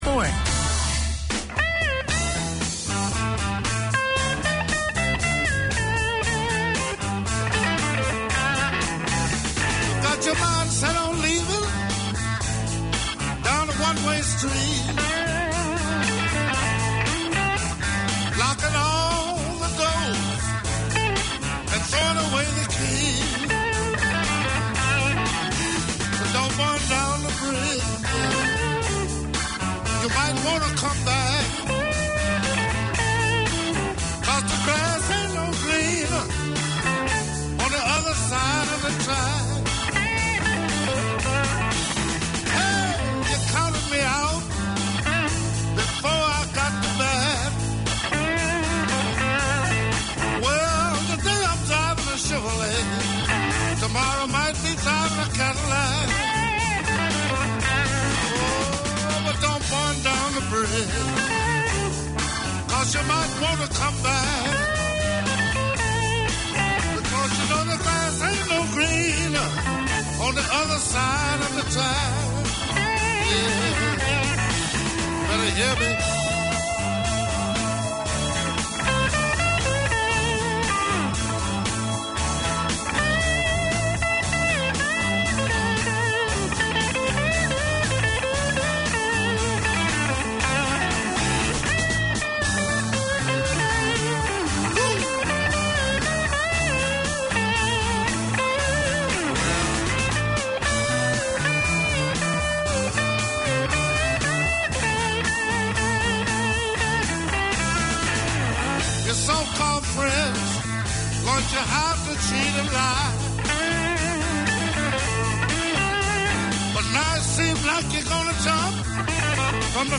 Community Access Radio in your language - available for download five minutes after broadcast.
The Filipino Show No shows scheduled this week Community magazine Language